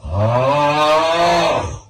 snore-1.ogg